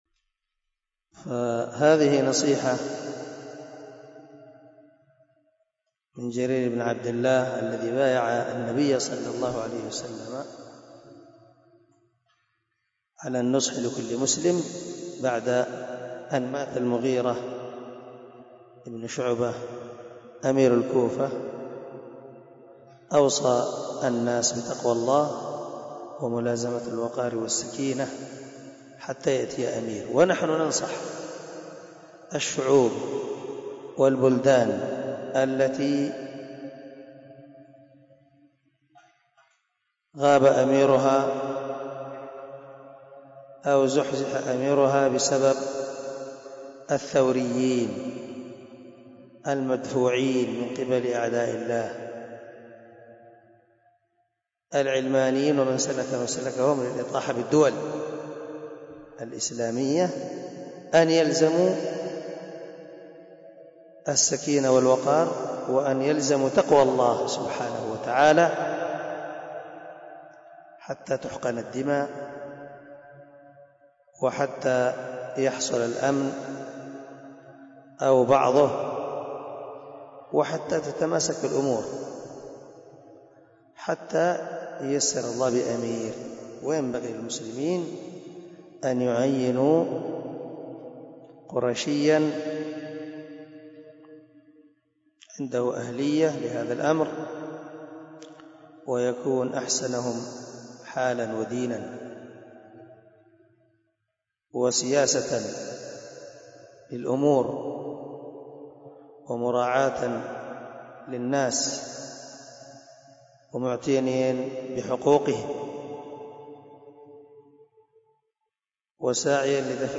056الدرس 1 من شرح كتاب العلم حديث رقم ( 59 ) من صحيح البخاري